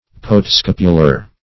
Search Result for " postscapular" : The Collaborative International Dictionary of English v.0.48: Postscapular \Post*scap"u*lar\, a. (Anat.)